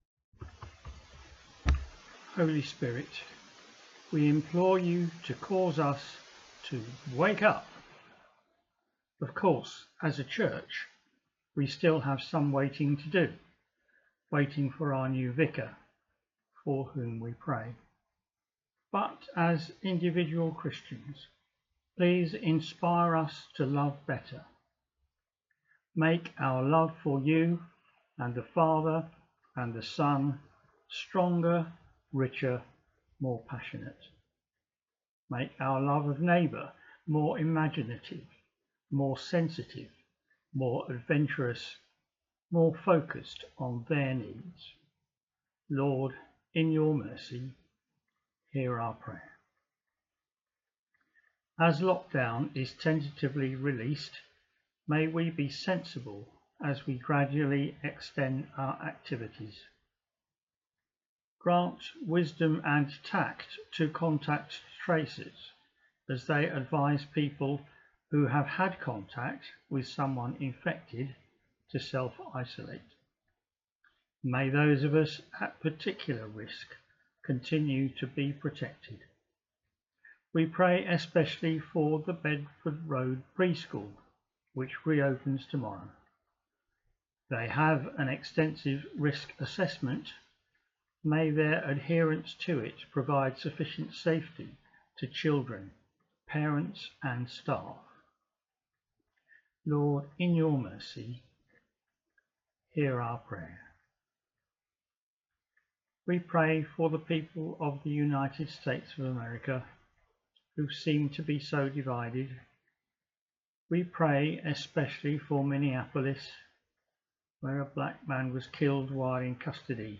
The Prayers